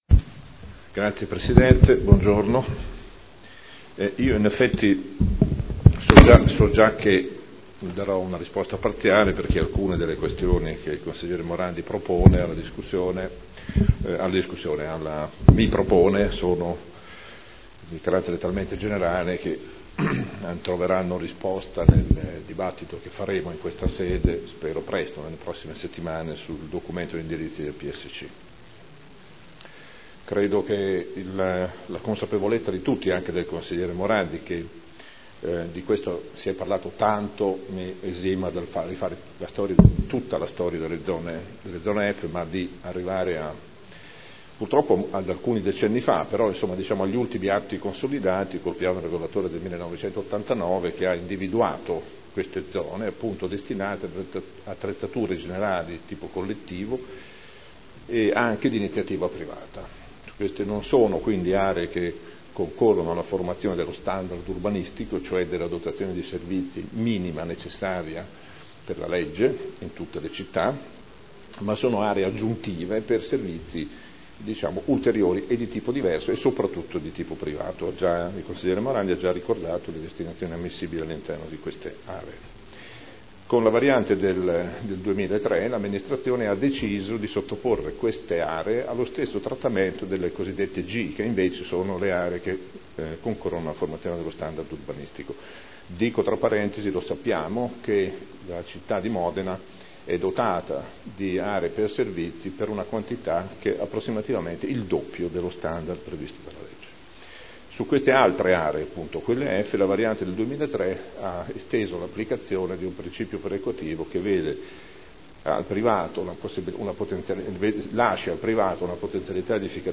Seduta del 15/10/2012 Risponde a Interrogazione del consigliere Morandi (PdL) avente per oggetto: "Aree F"